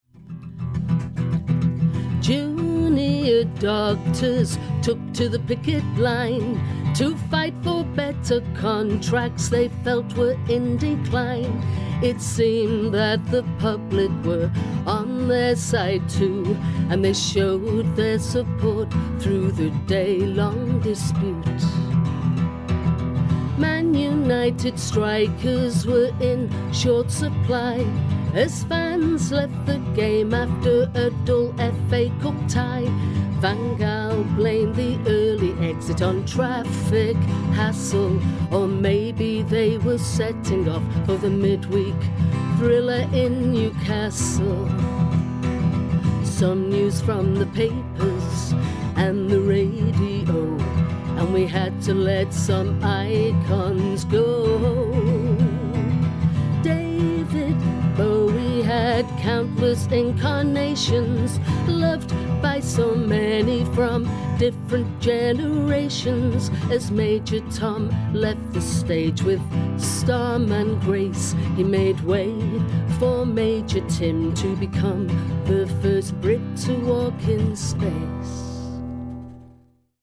news in song